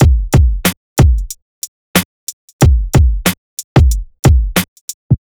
HP092BEAT2-L.wav